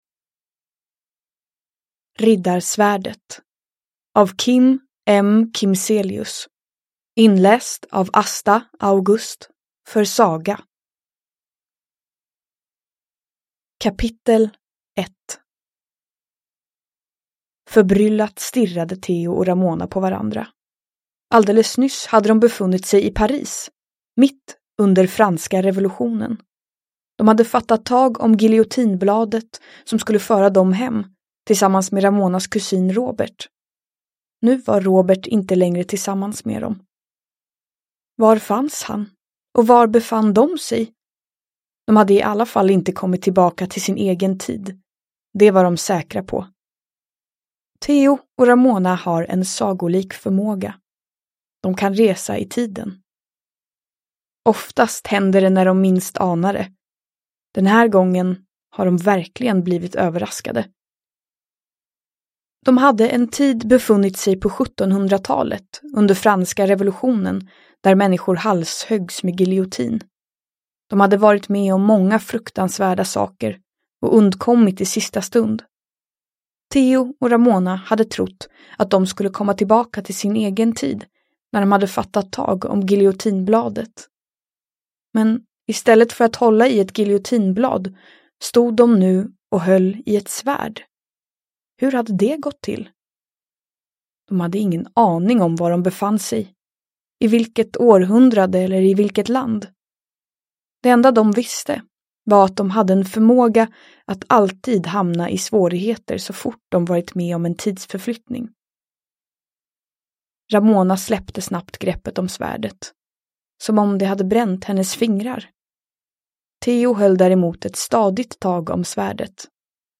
Riddarsvärdet / Ljudbok